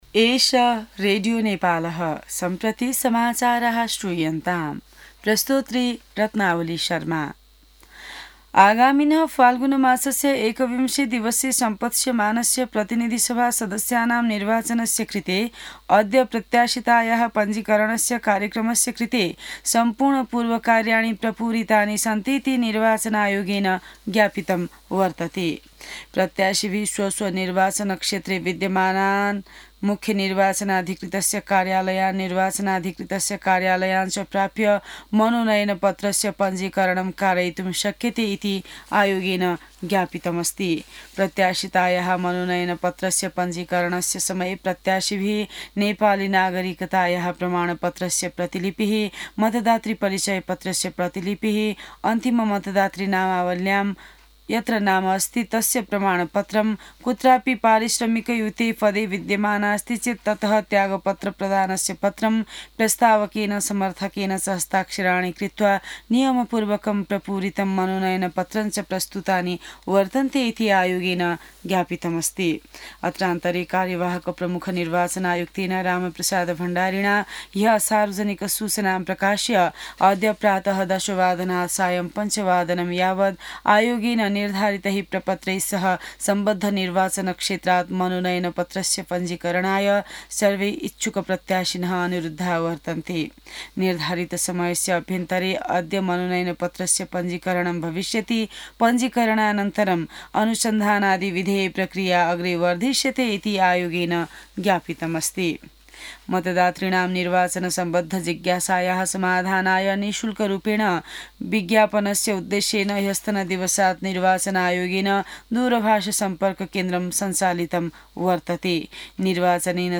An online outlet of Nepal's national radio broadcaster
संस्कृत समाचार : ६ माघ , २०८२